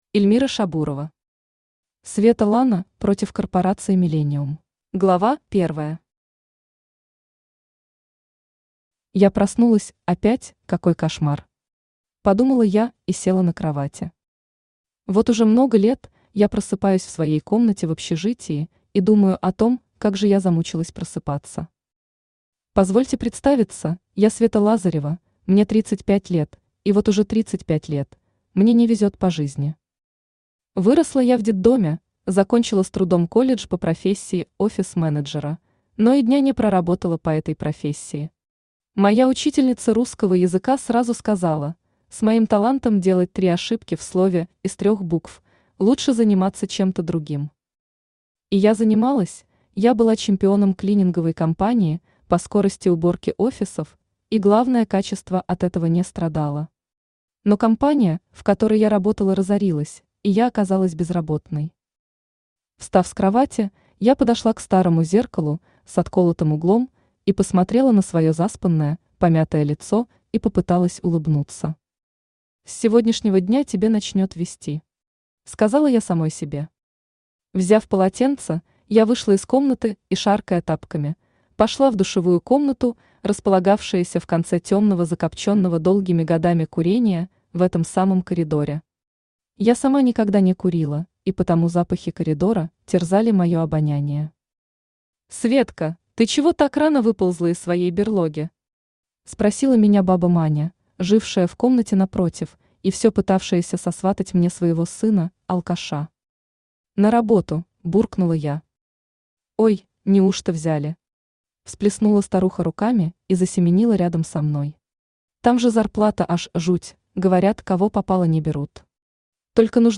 Аудиокнига Света Лана против корпорации «Миллениум» | Библиотека аудиокниг
Aудиокнига Света Лана против корпорации «Миллениум» Автор Эльмира Шабурова Читает аудиокнигу Авточтец ЛитРес.